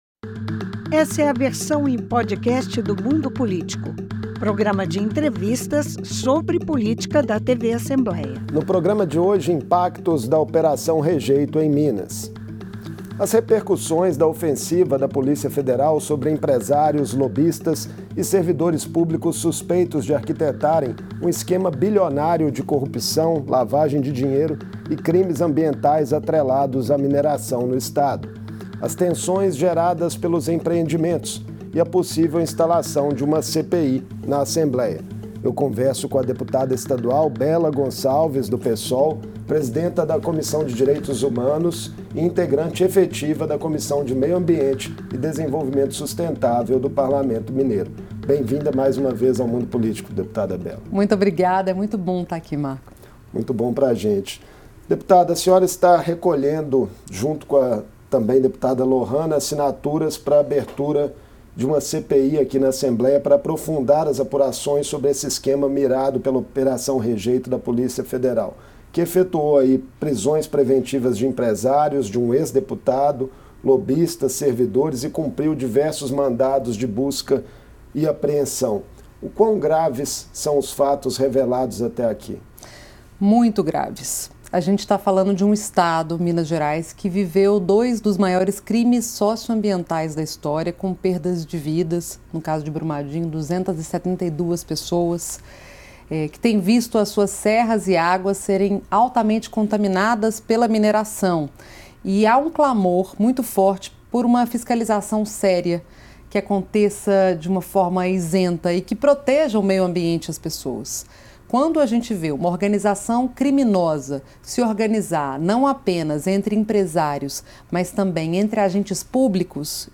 Parlamentares de oposição na Assembleia buscam assinaturas para a instalação de uma CPI que aprofunde as investigações da operação Rejeito da Polícia Federal. Servidores públicos, empresários e lobistas são suspeitos de arquitetarem um esquema bilionário de corrupção, lavagem de dinheiro e de crimes ambientais envolvendo as mineradoras Fleurs Global e Gute Schit. Em entrevista